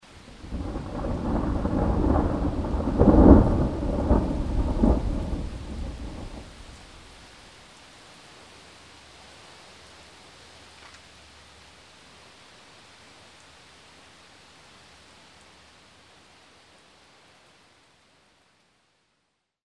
rain_thunder02.mp3